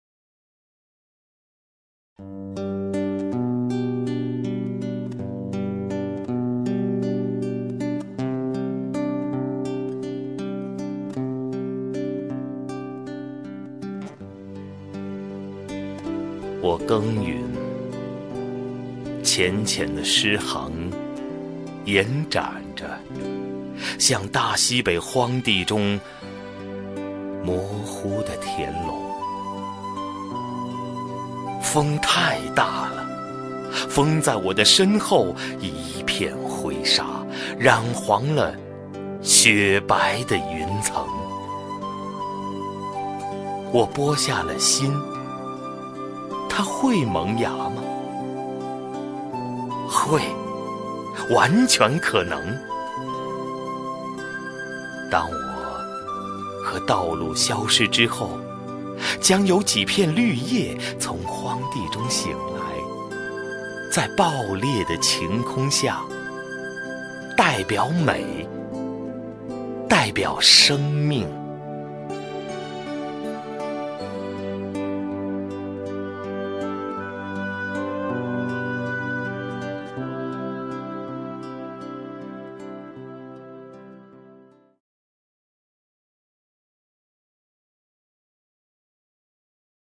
首页 视听 名家朗诵欣赏 赵屹鸥
赵屹鸥朗诵：《我耕耘》(顾城)